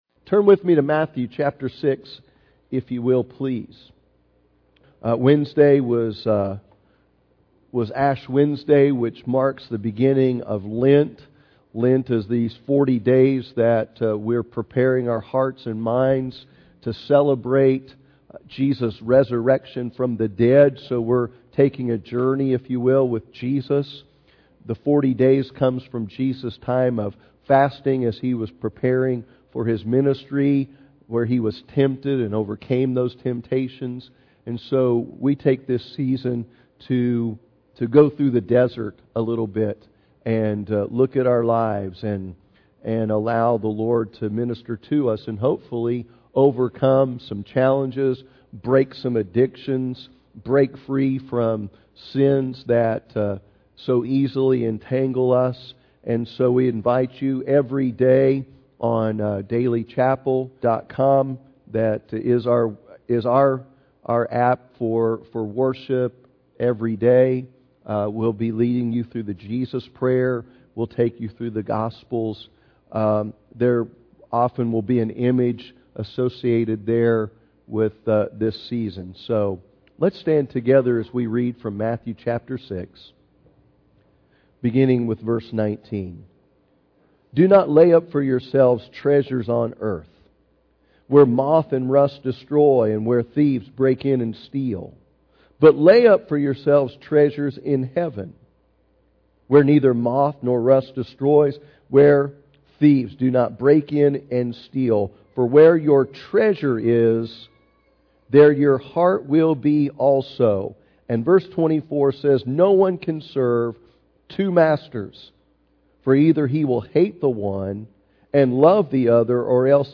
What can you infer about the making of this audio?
Sunday Morning Service The Blessed Life